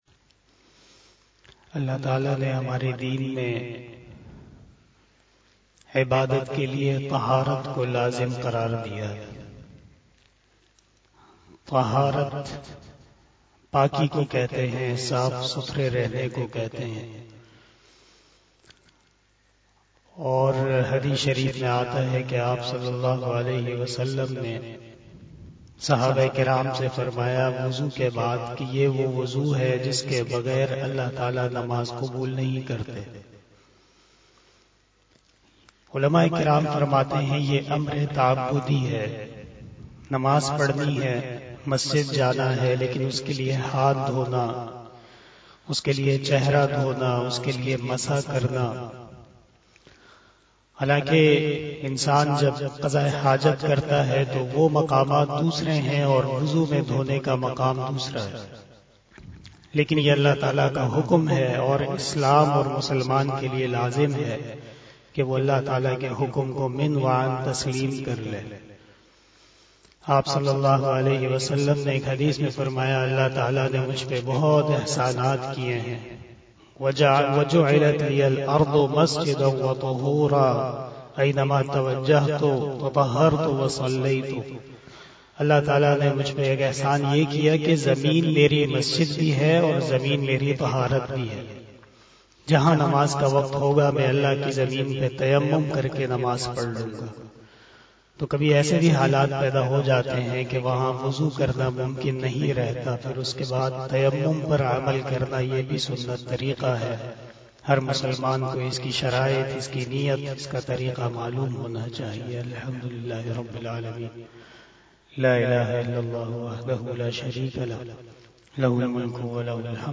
013 After Asar Namaz Bayan 19 February 2022 ( 17 Rajab ul Murajjab 1443HJ) Saturday